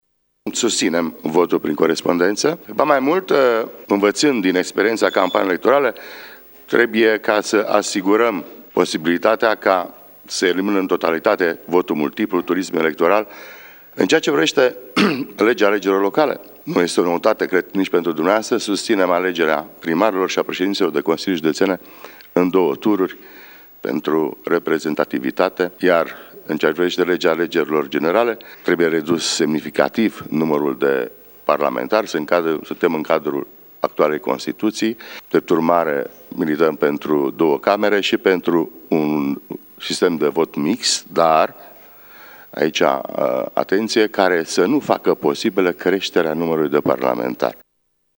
Iată ce a declarat copreşedintele PNL, Vasile Blaga: